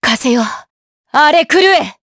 Seria-Vox_Skill4_jp.wav